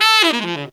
Index of /90_sSampleCDs/Best Service ProSamples vol.25 - Pop & Funk Brass [AKAI] 1CD/Partition C/TENOR FX2